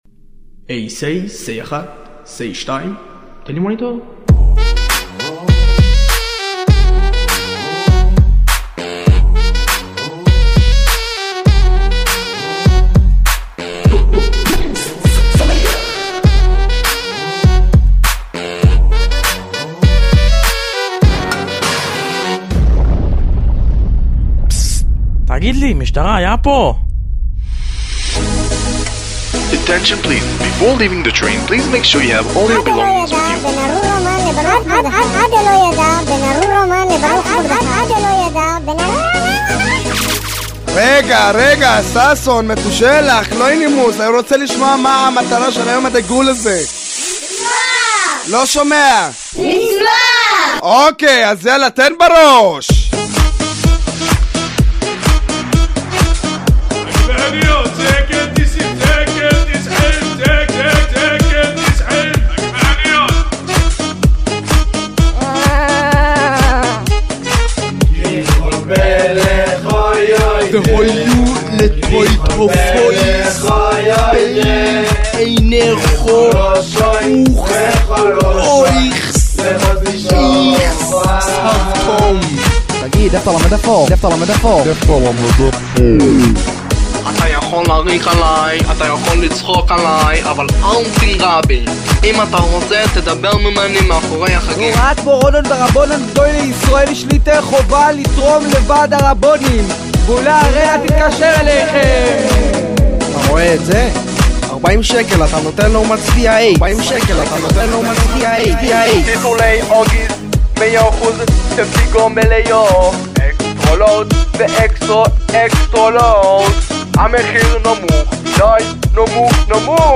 "שיר" שהוקלט עבור ישיבה לפורים.